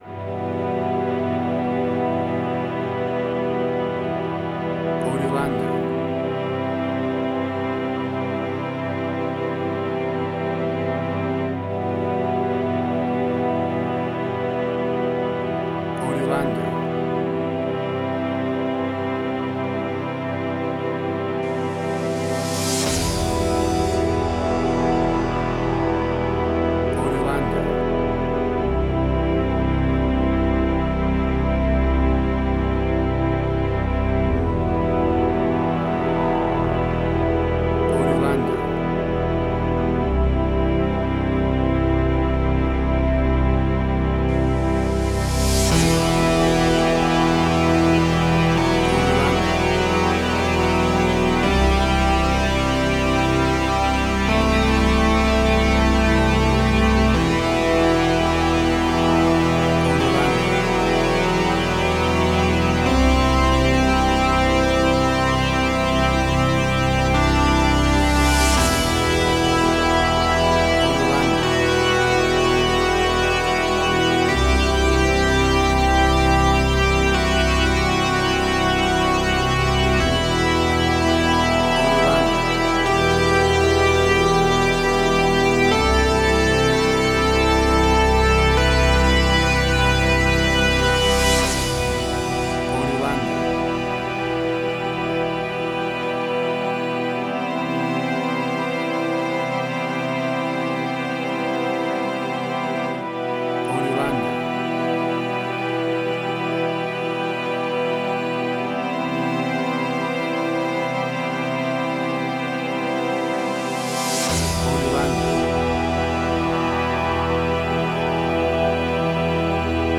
WAV Sample Rate: 16-Bit stereo, 44.1 kHz
Tempo (BPM): 84